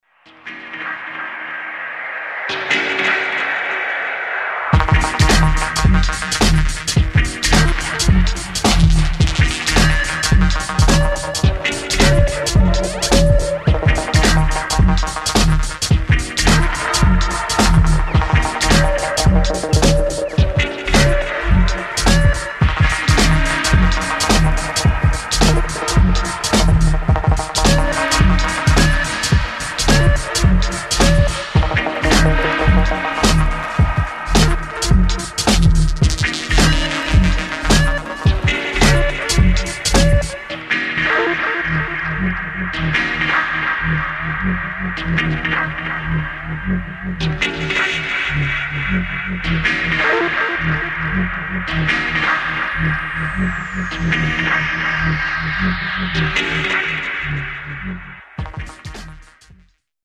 [ REGGAE / DUB ]